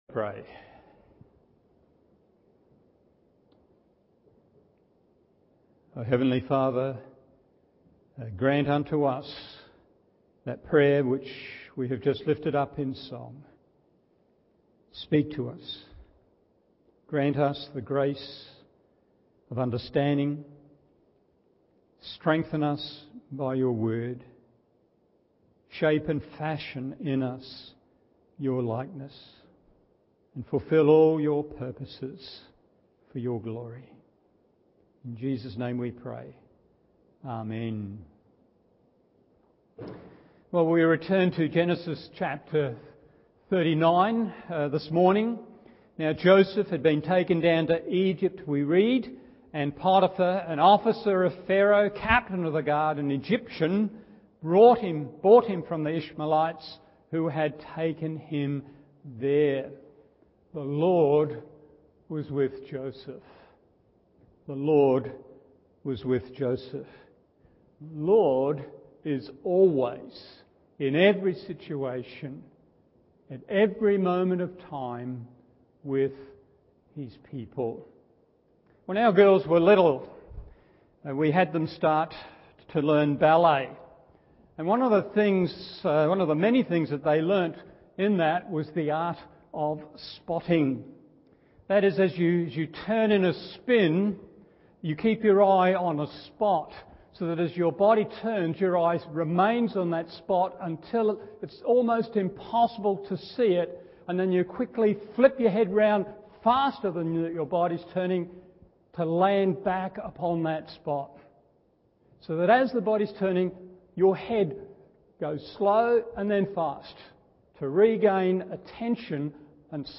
Morning Service Genesis 39:1-16a 1.